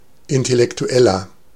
Ääntäminen
Synonyymit mental Ääntäminen France: IPA: [ɛ̃.te.lɛk.tɥɛl] Haettu sana löytyi näillä lähdekielillä: ranska Käännös Ääninäyte Adjektiivit 1. intellektuell 2. geistig Substantiivit 3.